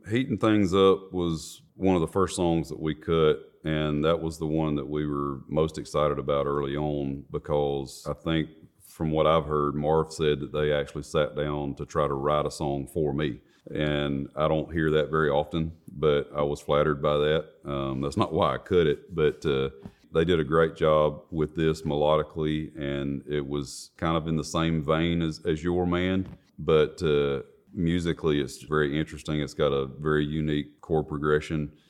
Josh Turner talks about his new song, "Heatin' Things Up."